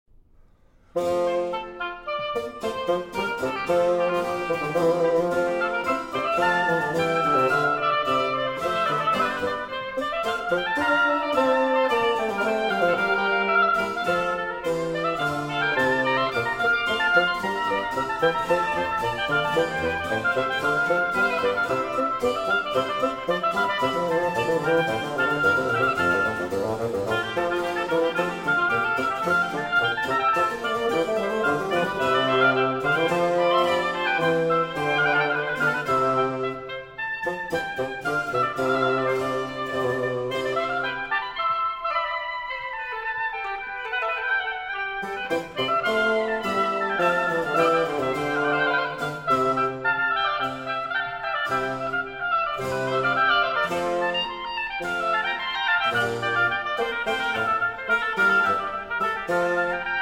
Oboe
Bassoon
Harpsichord
from Trio Sonata in F Major